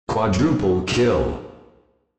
Announcer
QuadrupleKill.wav